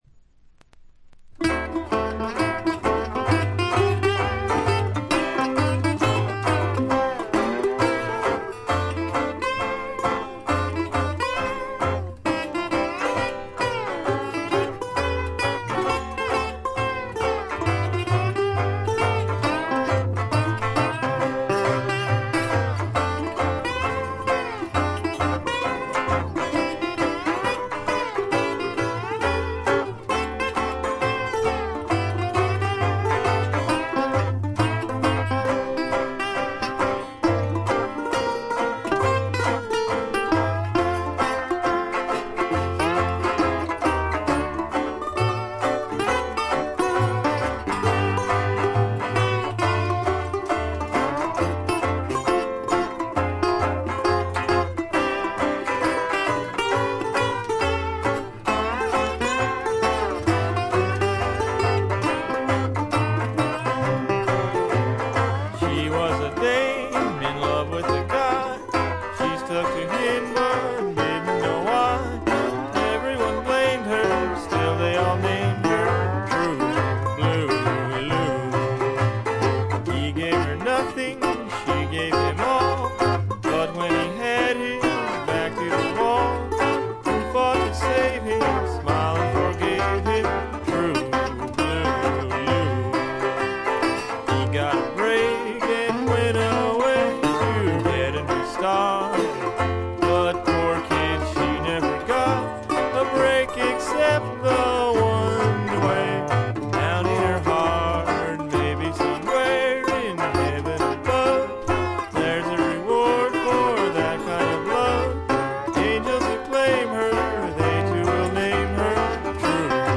ところどころでチリプチ少々、散発的なプツ音少々。
戦前のブルース、ジャズ、ラグ、ストリングバンドといった世界をどっぷりと聴かせてくれます。
文字通りのチープで素人くささが残る演奏が愛すべき作品です。
試聴曲は現品からの取り込み音源です。